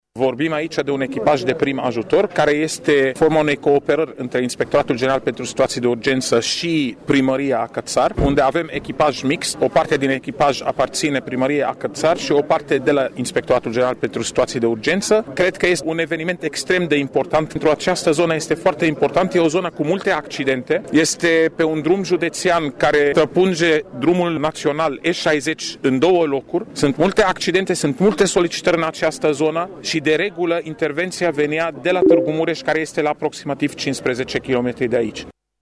Secretarul de stat, Raed Arafat, a arătat că înfiinţarea acestui punct este deosebit de importantă deoarece în zonă se produc foarte multe accidente: